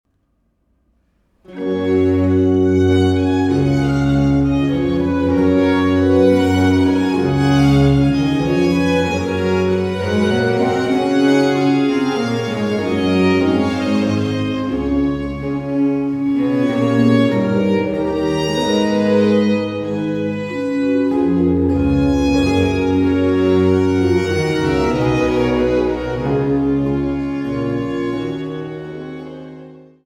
Instrumetalmusik für Hof, Kirche, Oper und Kammer